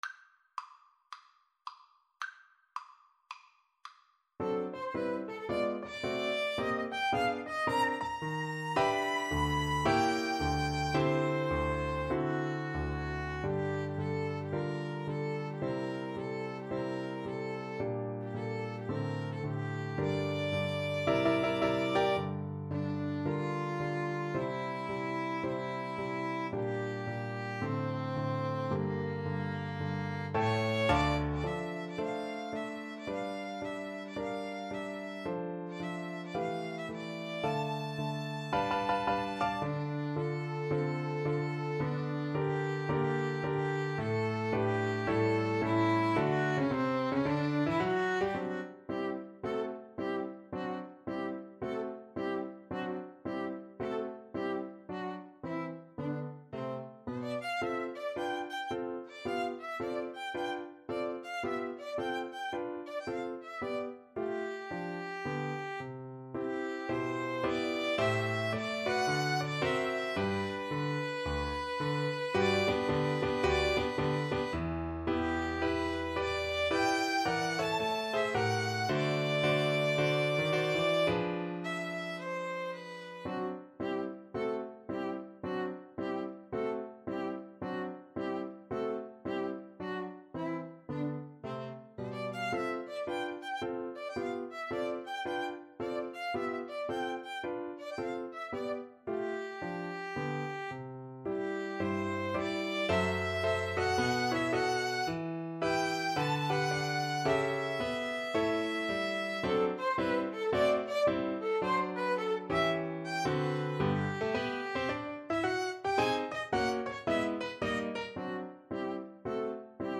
Moderato =110 swung